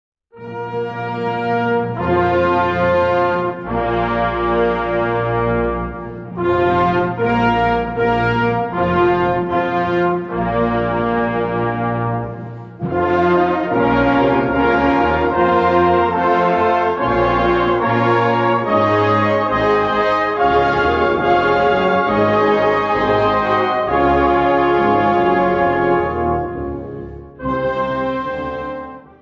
Gattung: Drei meditative Klangbilder
Besetzung: Blasorchester